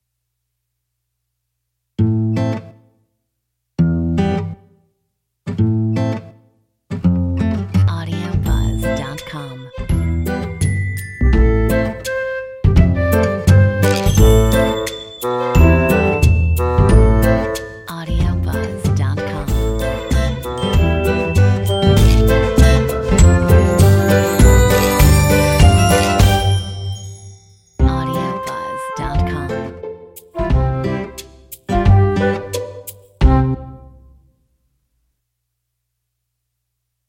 Metronome 92